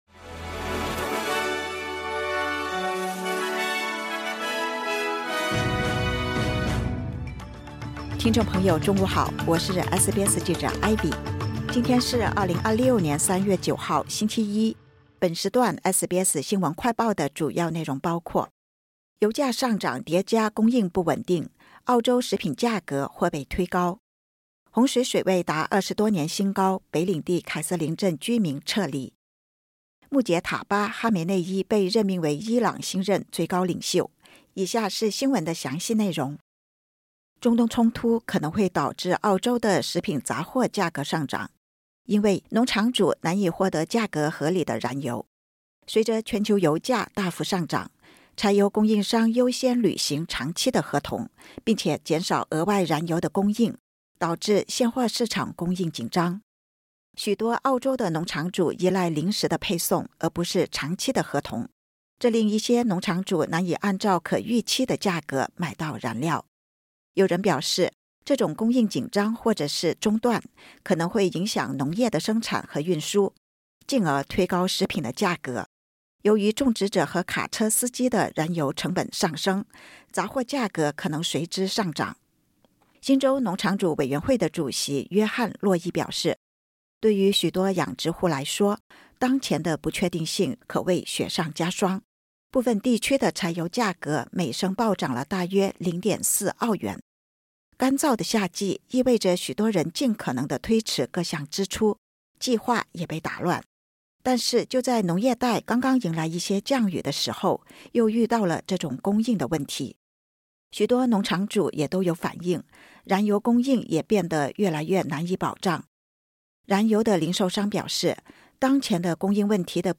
【SBS新闻快报】油价上涨叠加供应因素 澳洲食品杂货价格或被推高